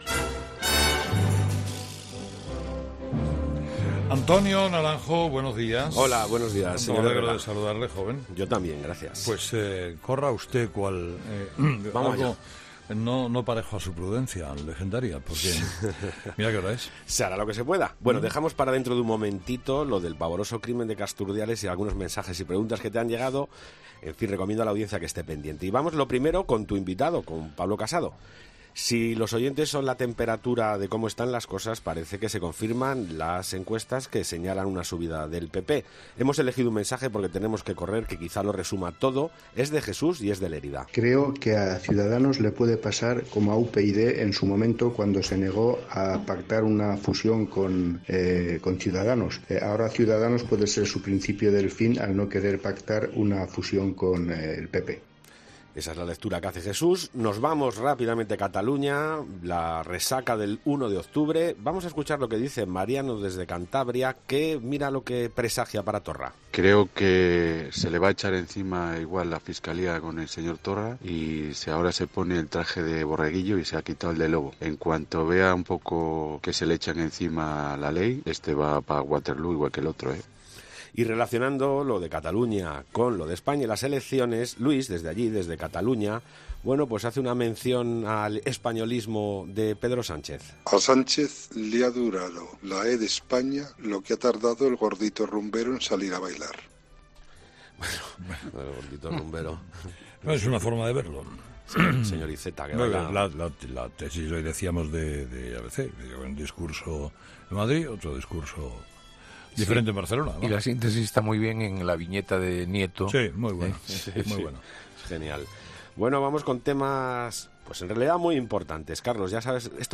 Avalancha de mensajes en el contestador de “Herrera en COPE”.